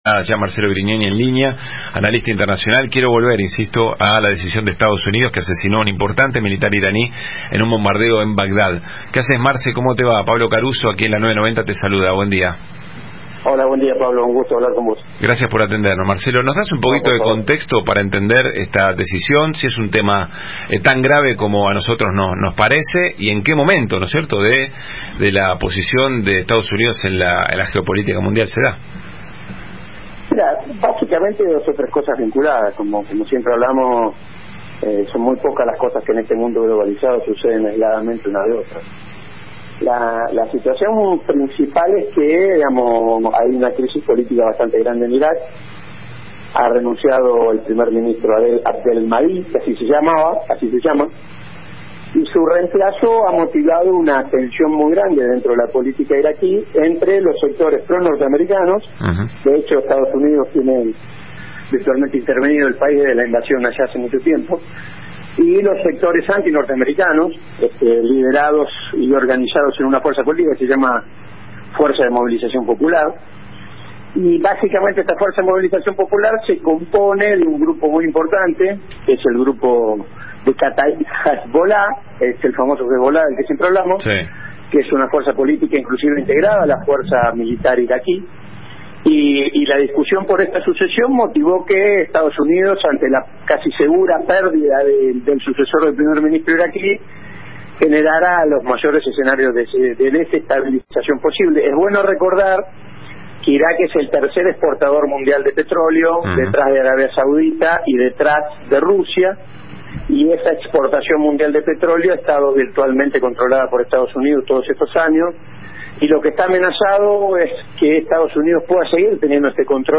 Radio AM990 de Buenos Aires